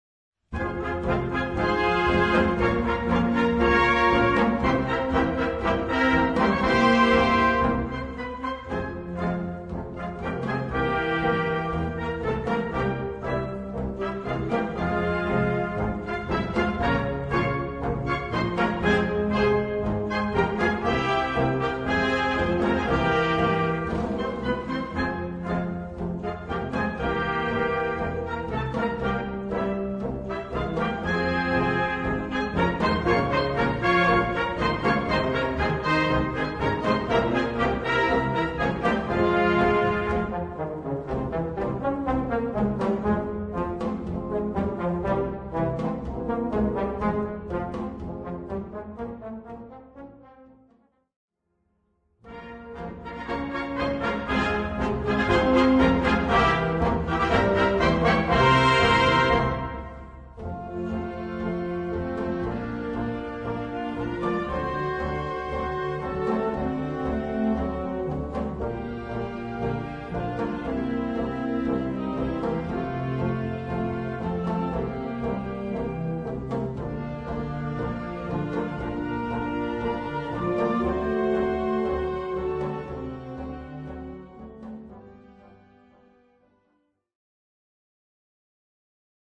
Gattung: Tango
Besetzung: Blasorchester